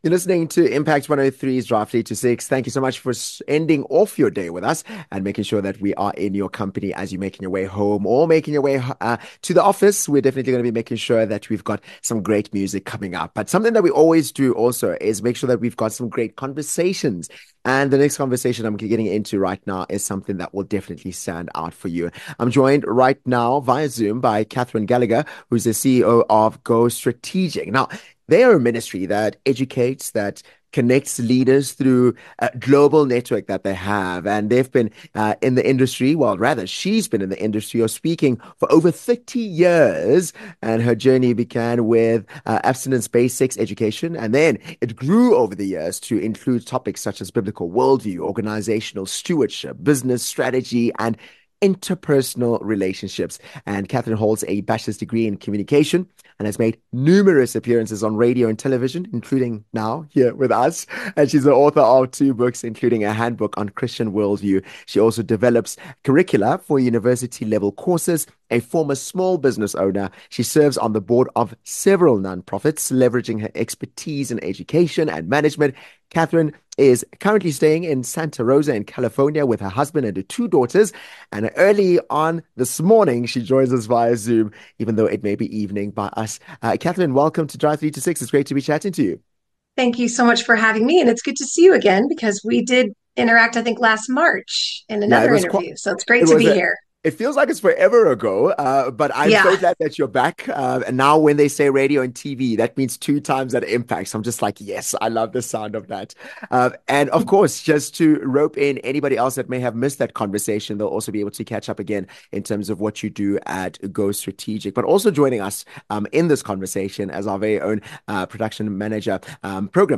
Through online schools, events, and resources, GoStrategic inspires individuals to rebuild, repair, and restore with Kingdom-driven principles. Discover how faith and leadership come together to create lasting change in this impactful conversation.